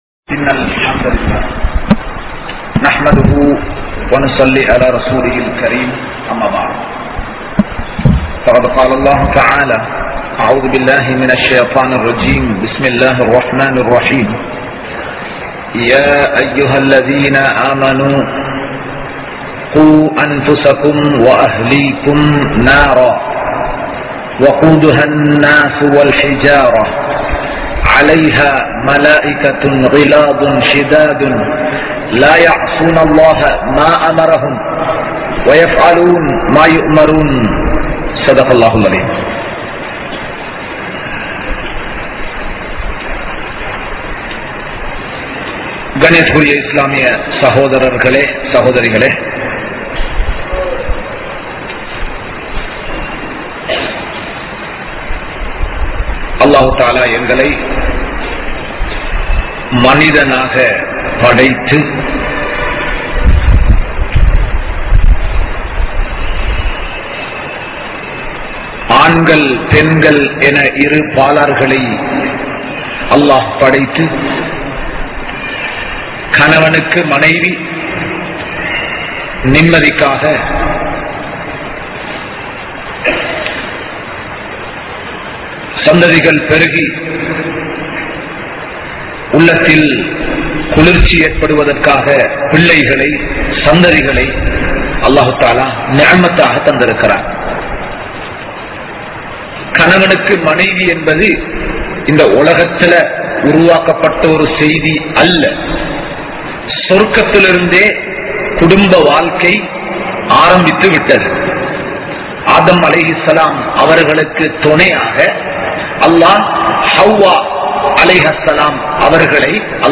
Islamiya Kudumba Vaalkai | Audio Bayans | All Ceylon Muslim Youth Community | Addalaichenai